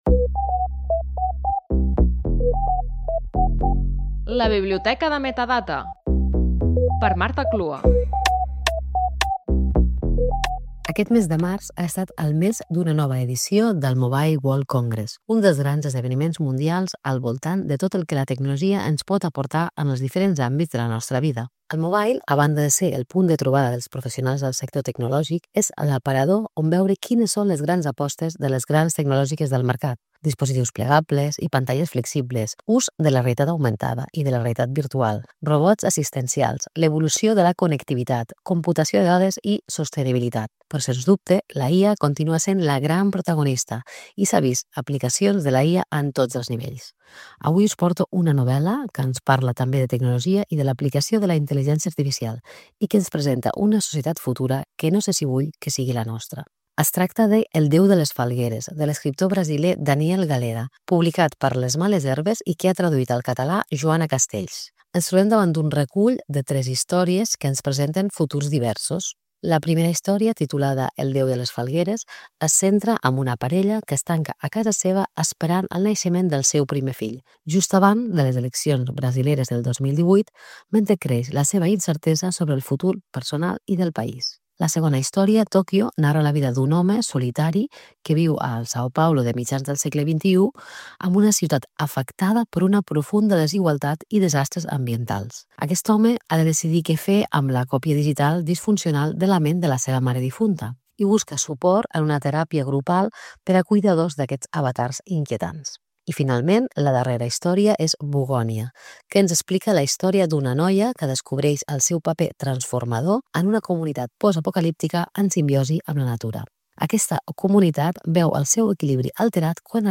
Careta del programa i espai dedicat al llibre "El déu de les falgueres" d’en Daniel Galera. El llibre recull tres històries de futurs diversos que parlen de la intel·ligència artificial i les tecnologies que la complementen.